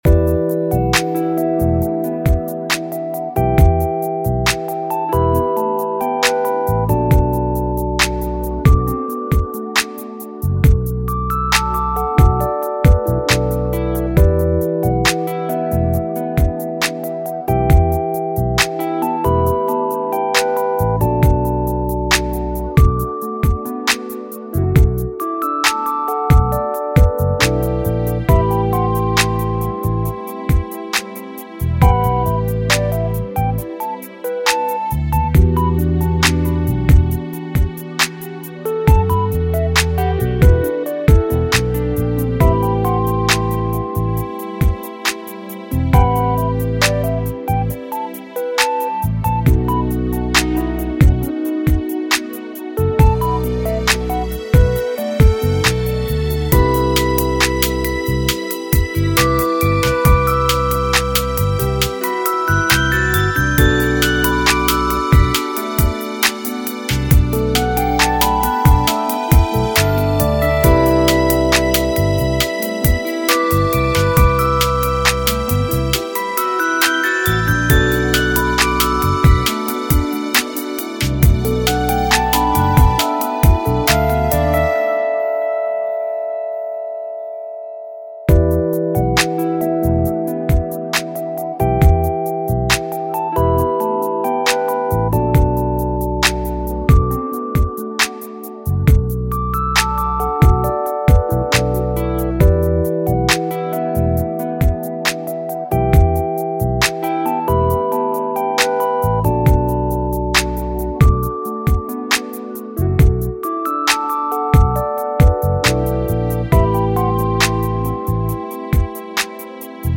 this time by the legendary big band master(s